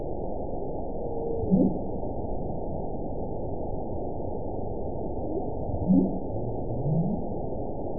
event 921794 date 12/19/24 time 03:08:05 GMT (4 months, 2 weeks ago) score 8.30 location TSS-AB02 detected by nrw target species NRW annotations +NRW Spectrogram: Frequency (kHz) vs. Time (s) audio not available .wav